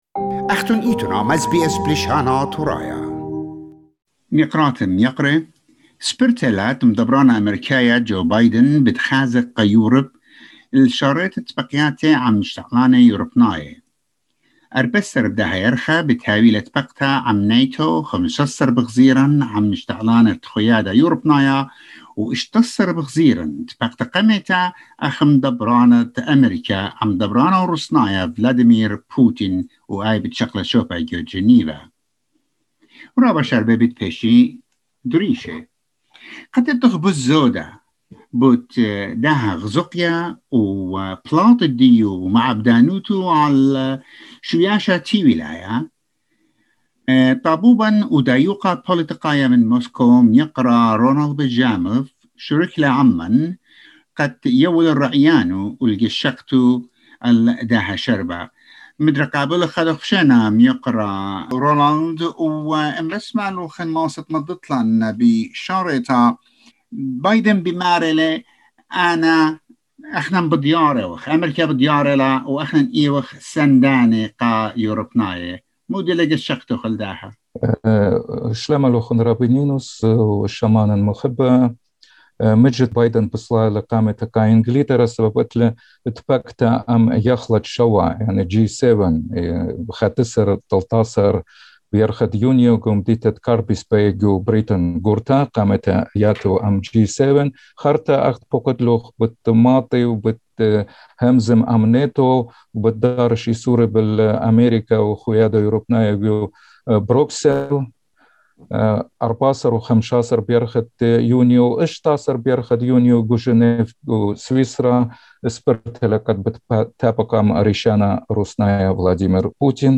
SKIP ADVERTISEMENT More details about this trip will be explained in this report.